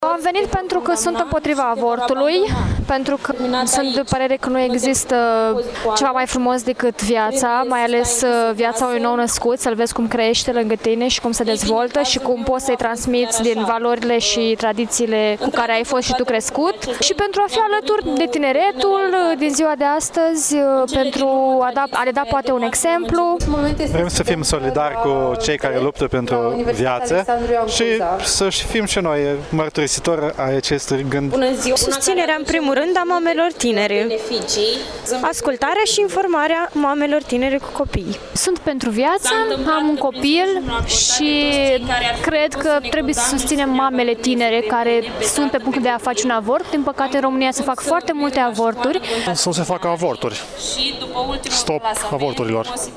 Cei prezenţi s-au declarat împotriva avorturilor:
24-mar-ora-16-Vox-pop-mars.mp3